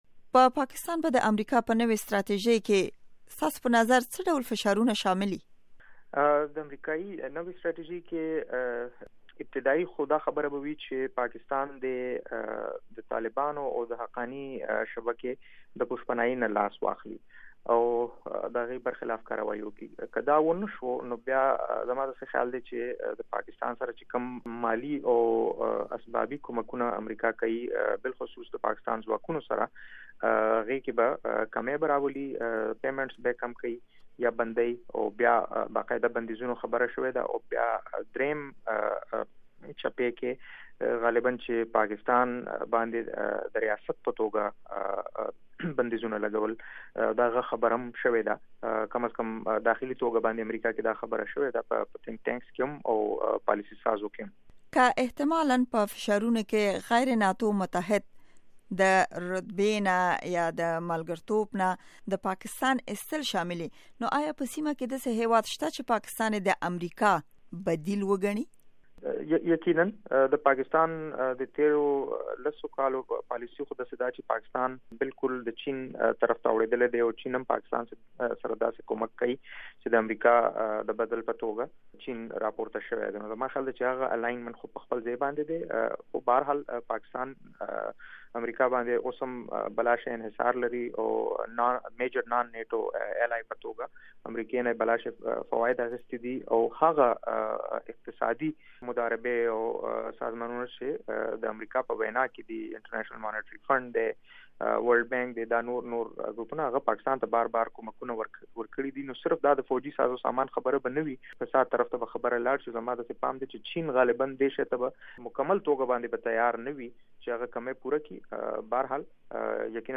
دامريکا غږ مرکه